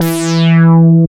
71.10 BASS.wav